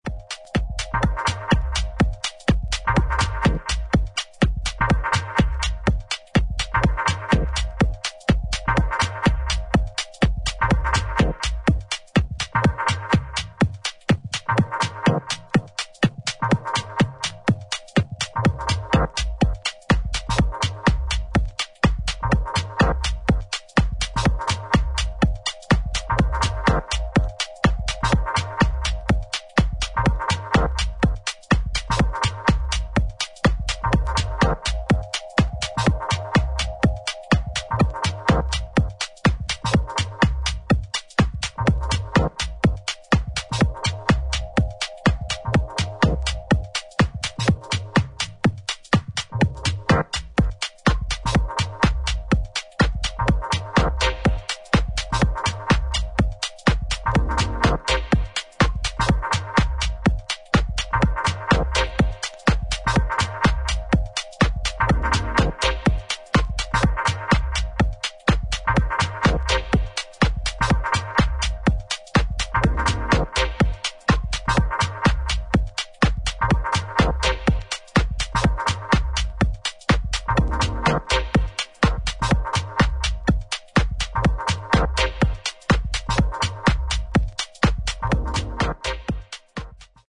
グルーヴを丁寧に刻んでいくテックハウス全4曲を収録